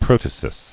[prot·a·sis]